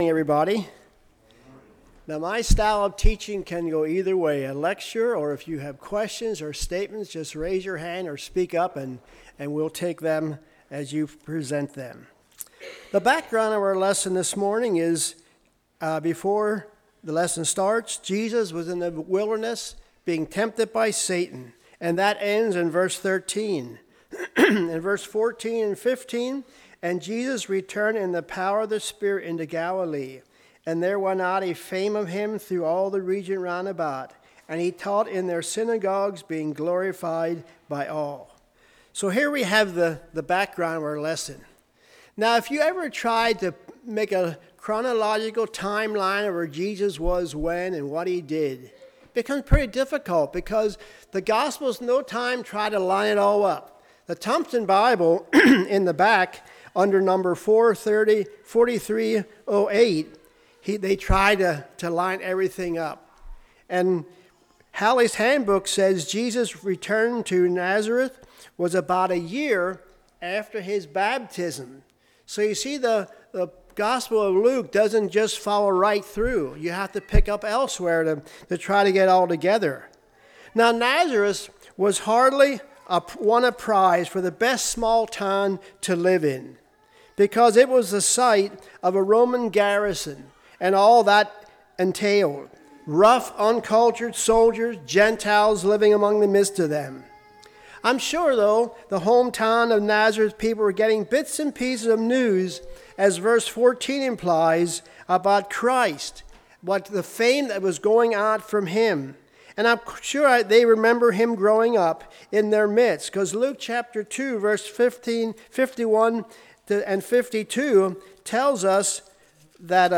Passage: Luke 4:16-32 Service Type: Sunday School